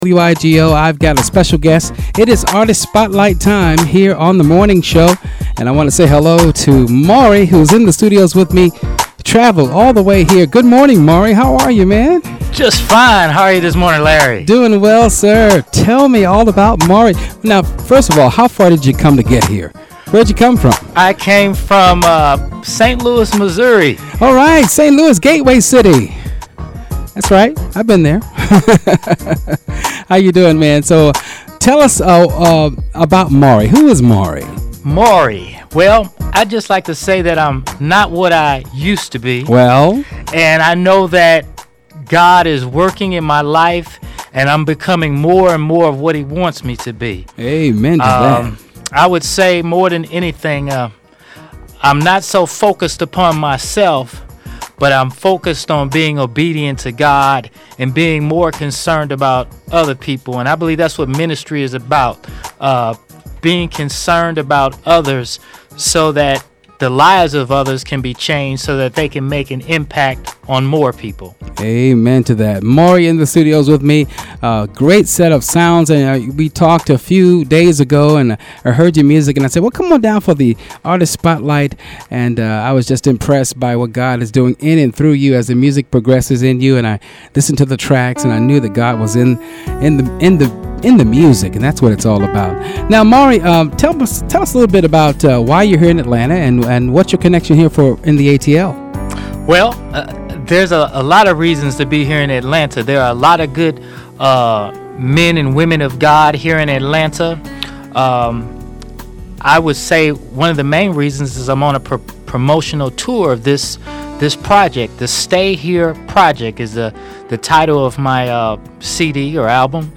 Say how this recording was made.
(music editted)